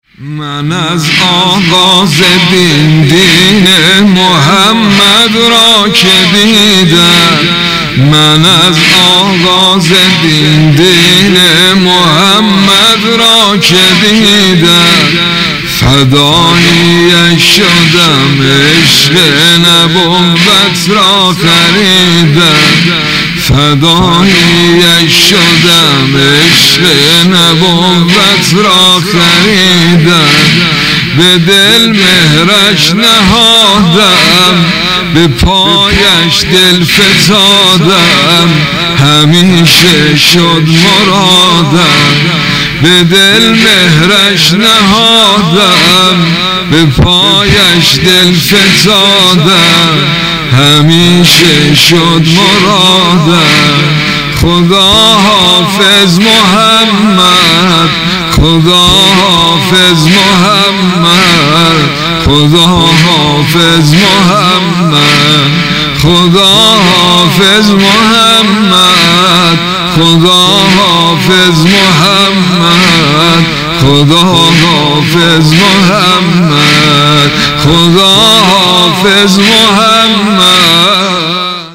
سبک ۵ ـ ذکر / ثلاث سنتی ـ دین